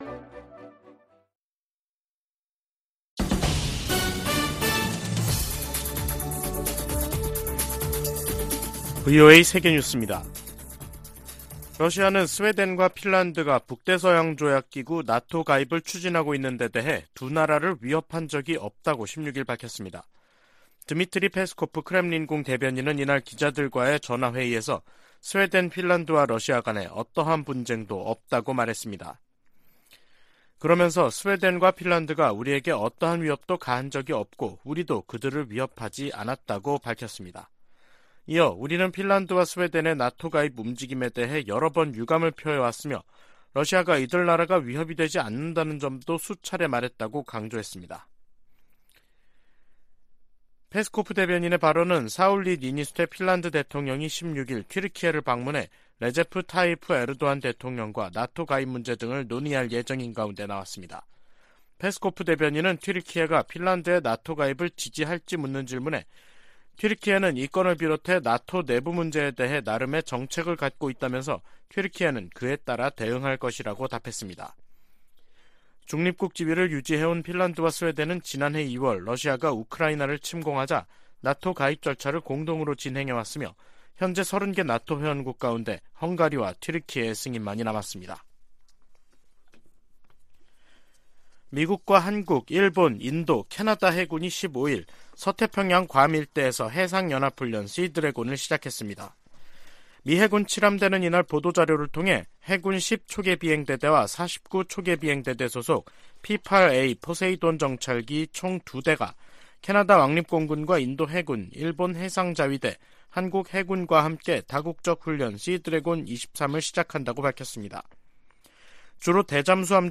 VOA 한국어 간판 뉴스 프로그램 '뉴스 투데이', 2023년 3월 16일 3부 방송입니다. 윤석열 한국 대통령과 기시다 후미오 일본 총리가 정상회담을 통해 북핵과 미사일 위협에 대응한 공조를 강화하기로 했습니다. 북한이 16일 ‘화성-17형’으로 추정되는 대륙간탄도미사일(ICBM)을 발사했습니다. 미국 정부는 북한의 ICBM 발사가 안보리 결의에 위배되고 역내 긴장을 고조시킨다며 강력 규탄했습니다.